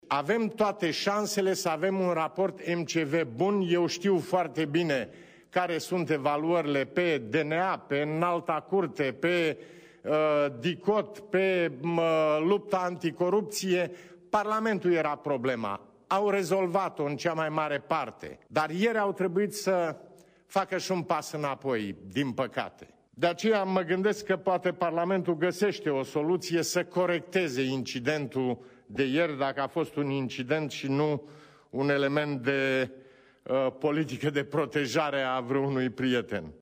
Preşedintele Traian Băsescu a susţinut, în această seară, o declaraţie la Palatul Cotroceni.
4-dec-rdj-20-Traian-Basescu-Parlament.mp3